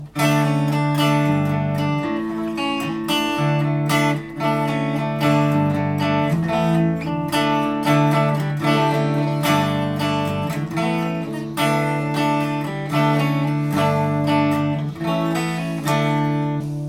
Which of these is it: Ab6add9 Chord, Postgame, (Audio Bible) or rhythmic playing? rhythmic playing